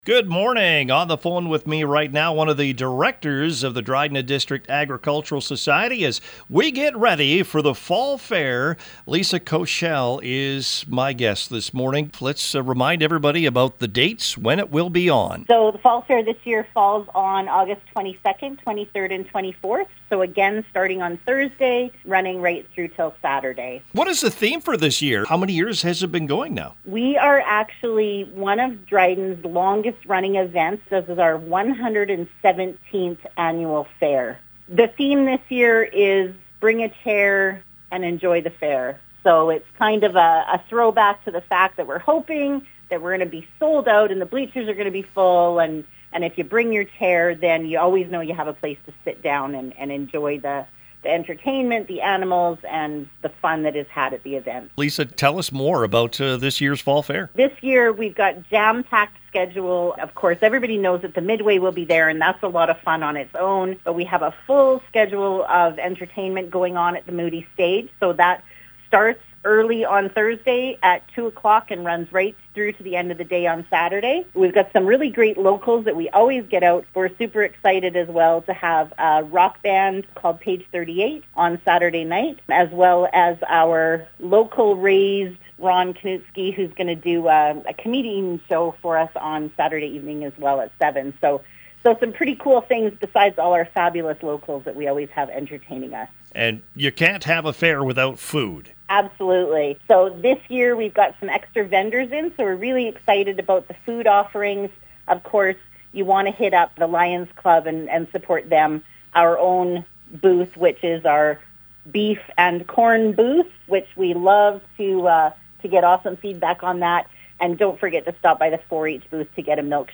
Dryden Fall Fair – Interview